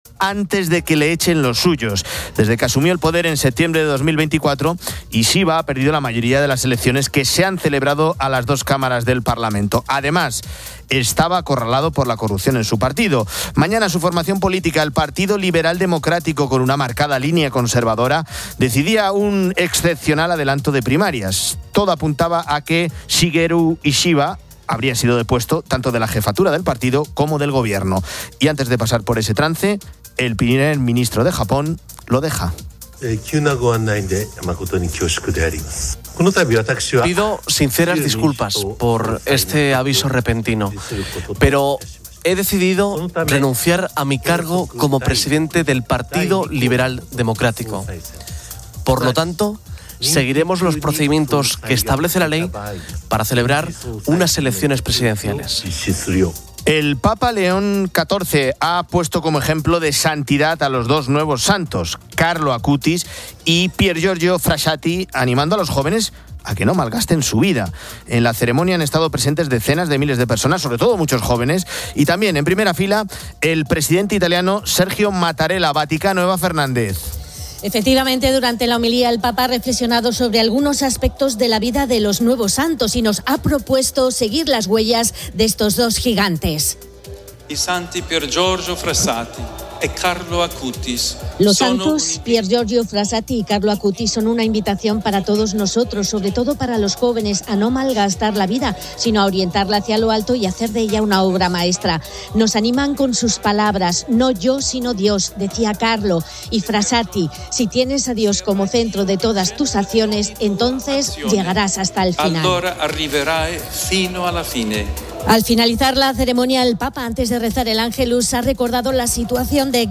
También, tertulia de chicas con los olvidos más célebres del equipo y de los oyentes.